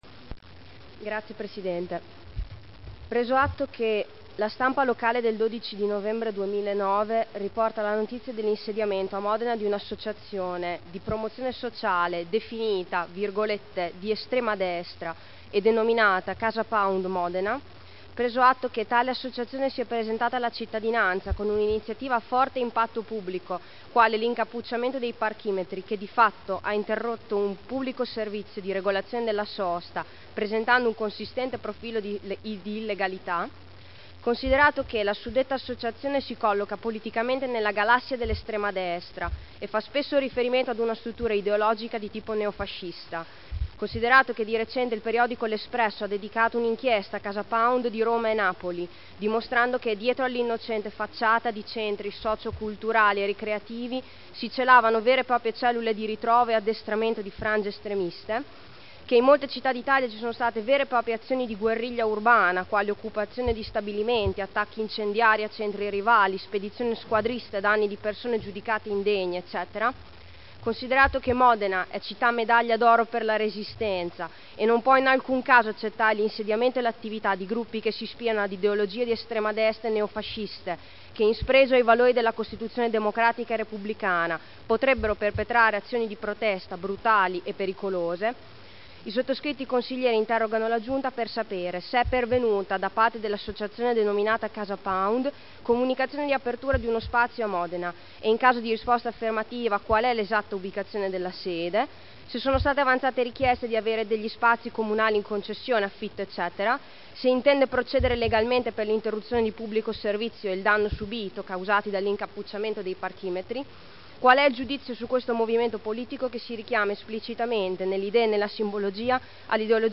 Seduta del 25/01/2010. Interrogazione dei consiglieri Morini, Trande, Guerzoni, Pini (P.D.) avente per oggetto: ““Casa Pound” a Modena? Iniziative “illegali” sui parchimetri e rischi per la città” – presentata il 16 novembre 2009 – in trattazione il 25.1.2010